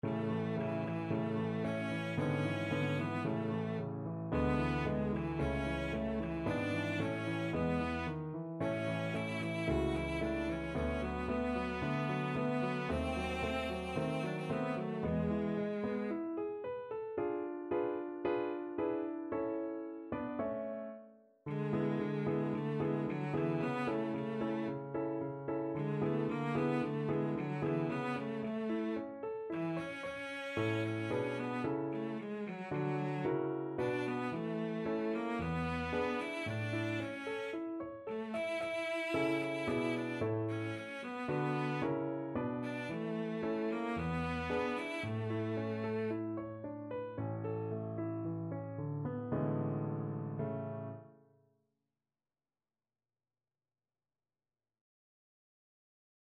2/4 (View more 2/4 Music)
~ = 56 Affettuoso
Classical (View more Classical Cello Music)